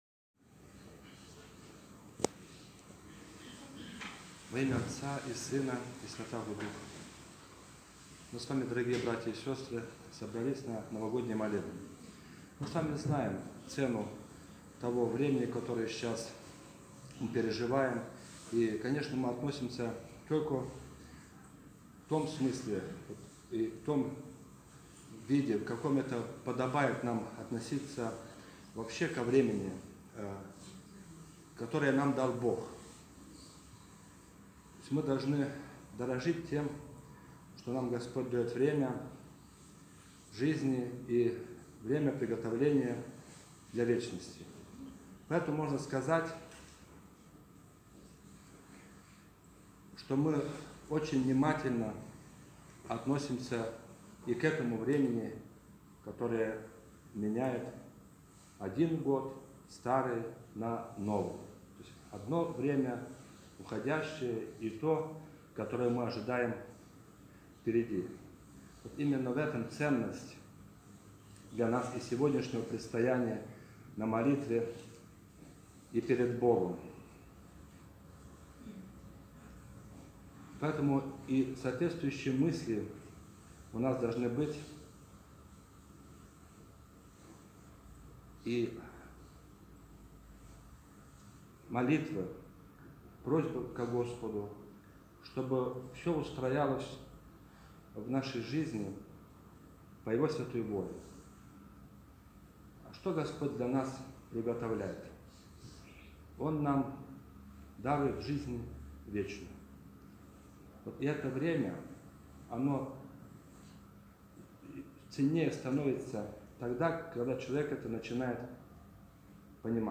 31 декабря 2021 года в храме святого праведного Иоанна Кронштадтского на Кронштадтской площади совершен Новогодний молебен.